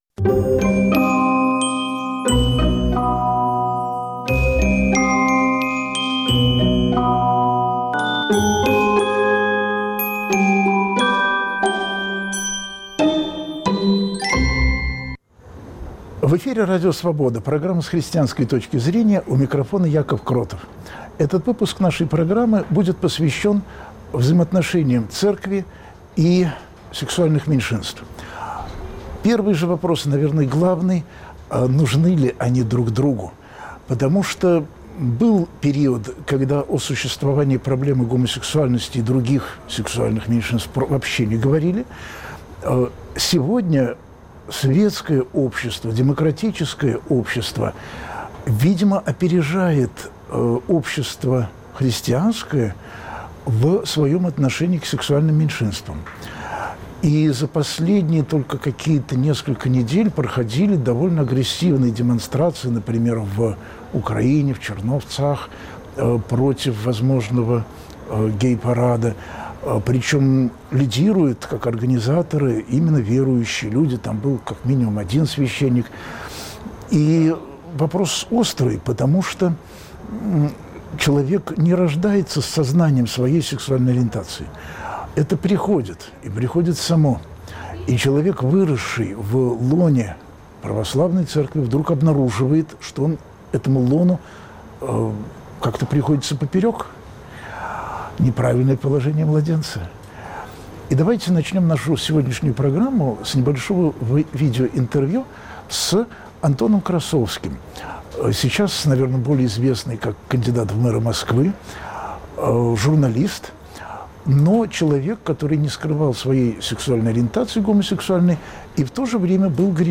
слушают друг друга верующие и неверующие